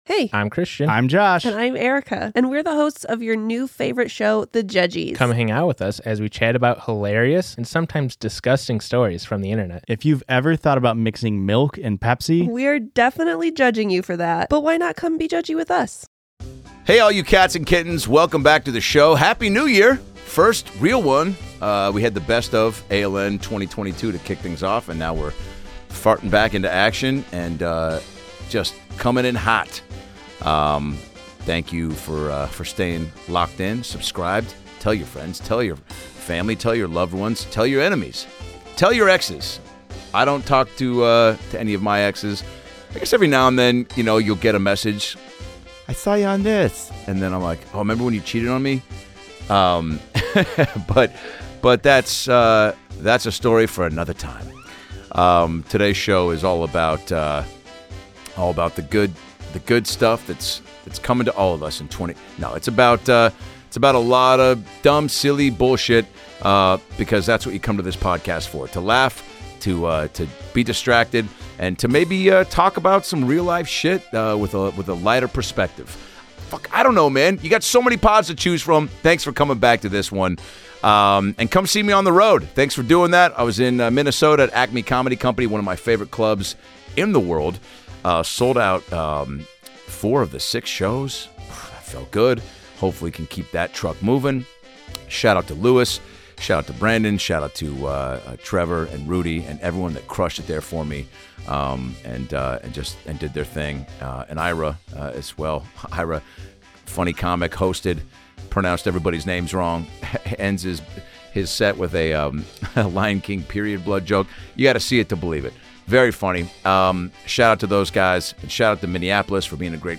First solo episode of the year!